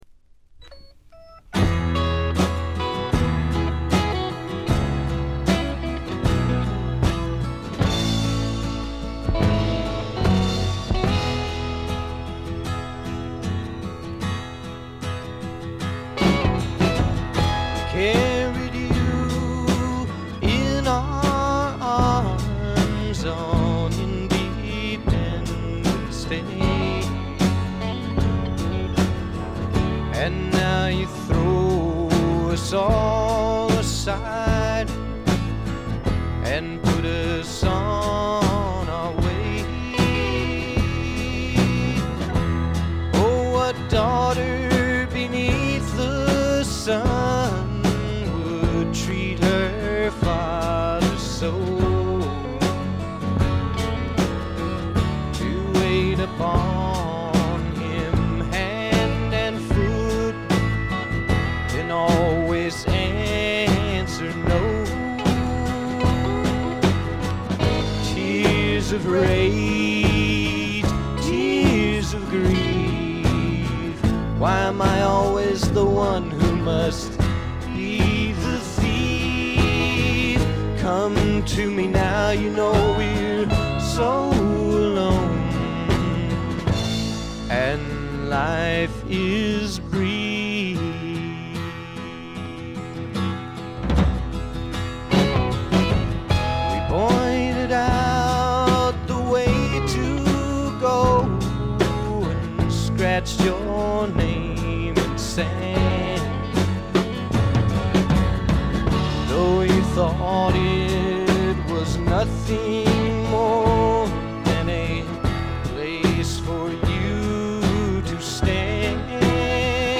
ほとんどノイズ感無し。
試聴曲は現品からの取り込み音源です。
Recorded at The Village Recorder